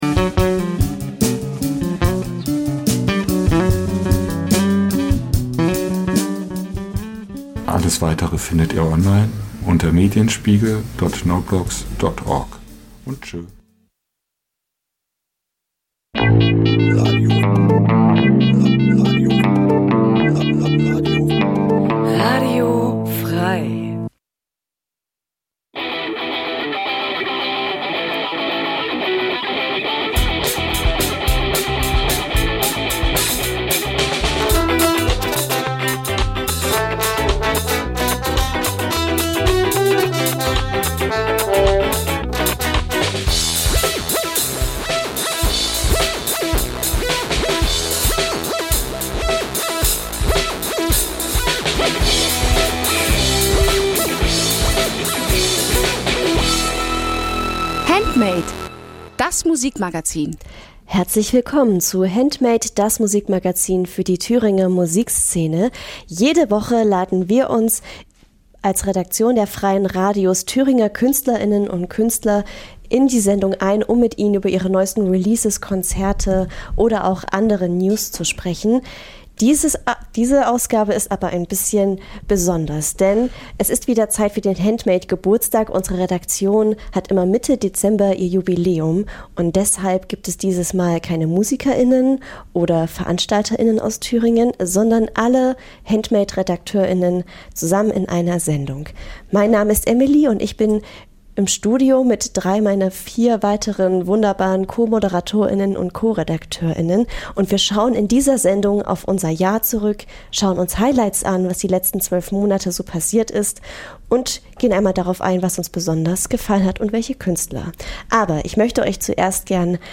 Jeden Donnerstag stellen wir euch regionale Musik vor und scheren uns dabei nicht um Genregrenzen. Ob Punk, Rap, Elektro, Liedermacher, oder, oder, oder � � wir supporten die Th�ringer Musikszene.
Regionale Musik Dein Browser kann kein HTML5-Audio.